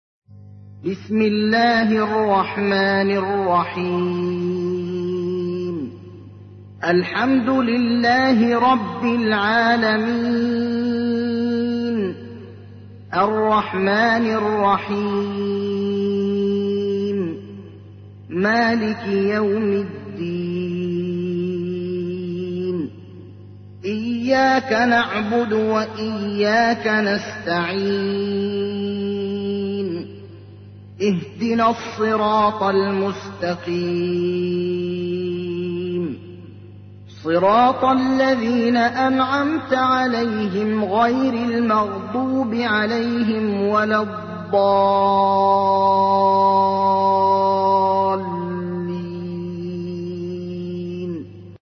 تحميل : 1. سورة الفاتحة / القارئ ابراهيم الأخضر / القرآن الكريم / موقع يا حسين